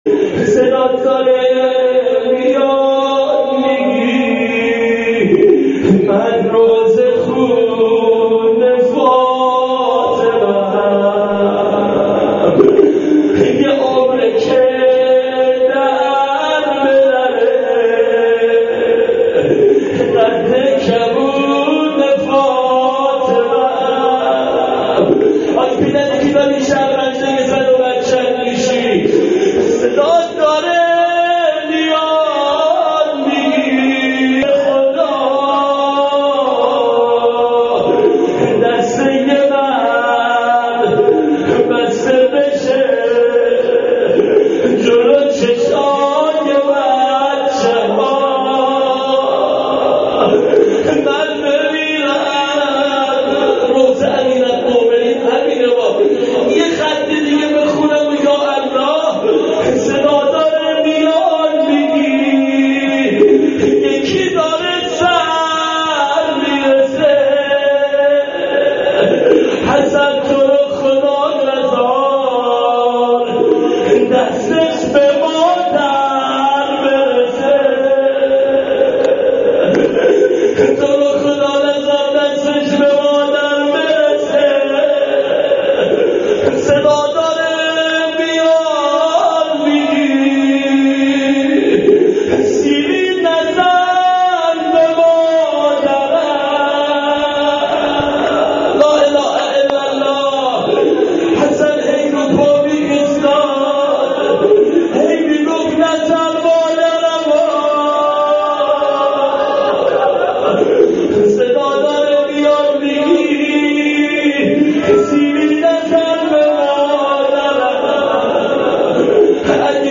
روضه-حضرت-زهرا-قبل-از-زیارت-عاشورا.mp3